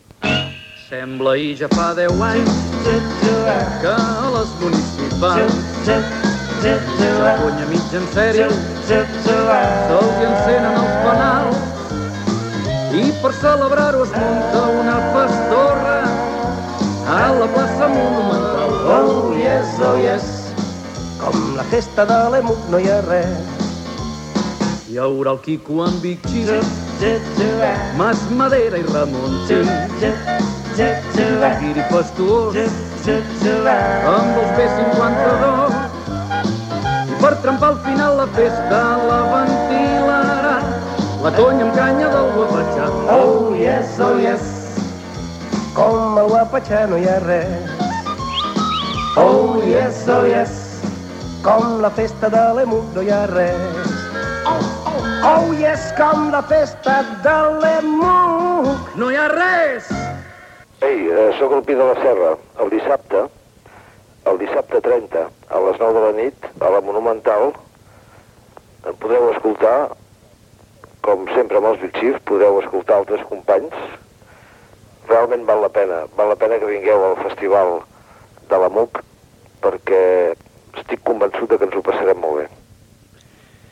Promo de la festa dels 10 anys d'Emissores Municipals, amb la invitació de Quico Pi de la Serra.
Música feta i interpretada per Huapachá Combo.